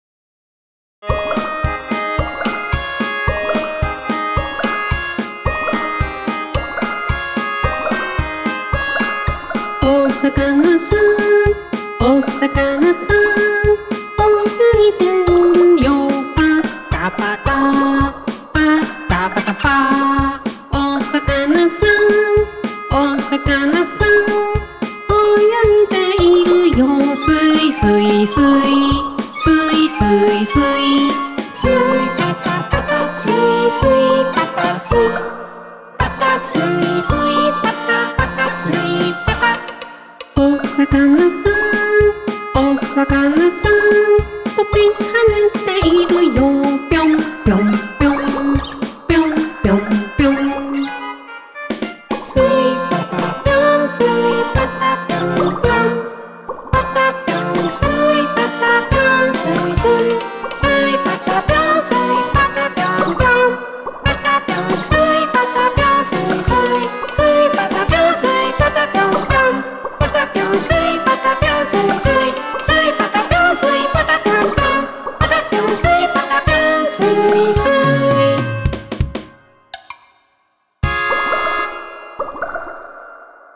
使用フリー振り付け・音楽
march-of-fish.mp3